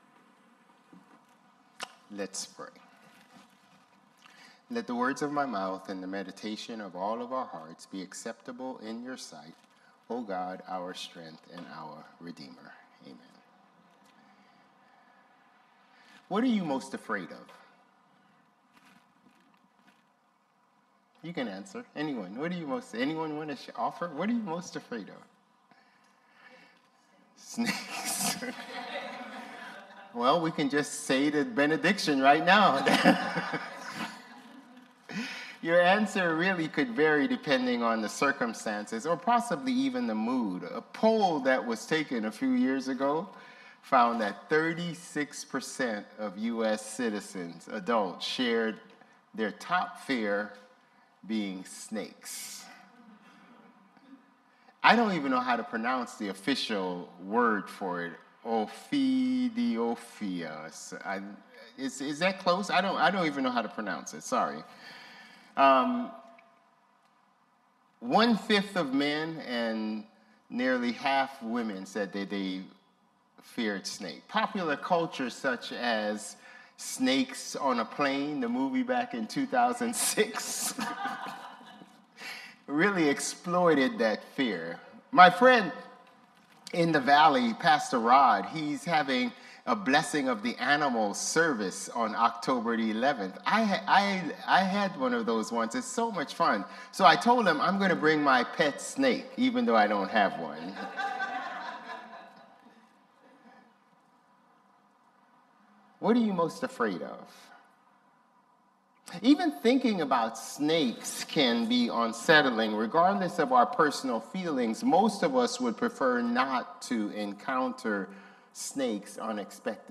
Sermons | Bethel Lutheran Church
September 28 Worship